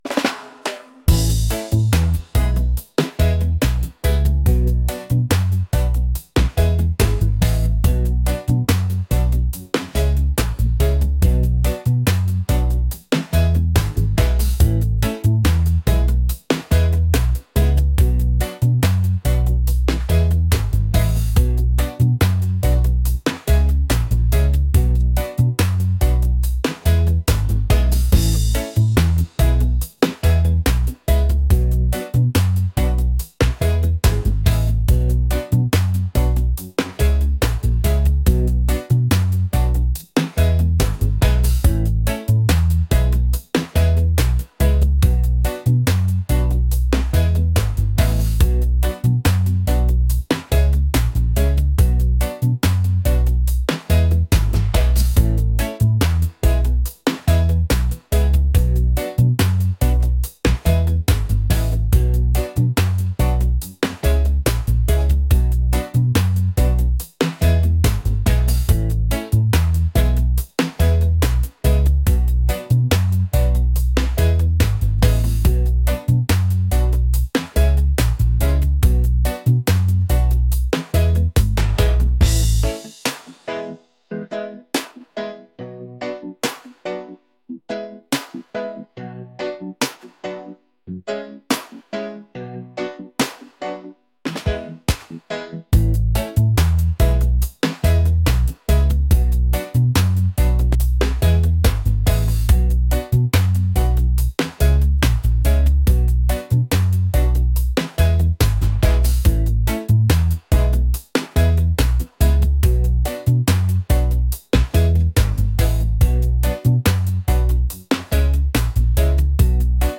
reggae | laid-back | island